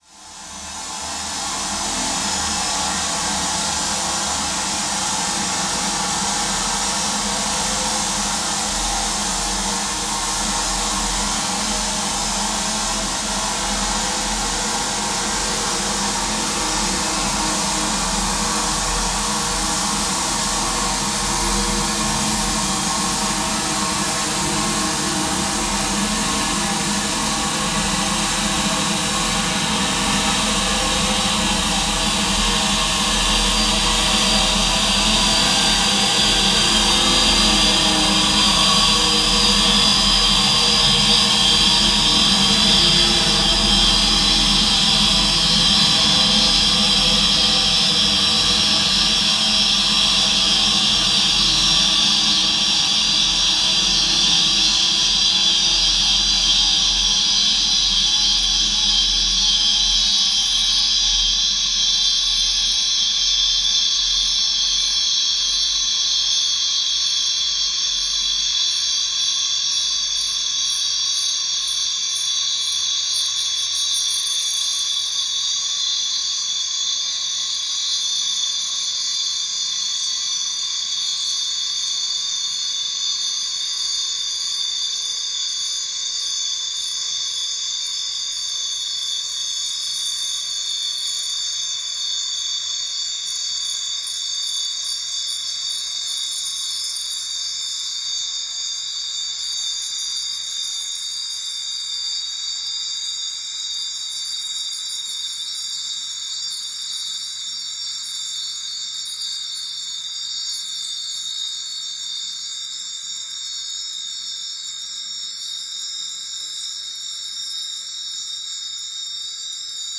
for surround
electronic sounds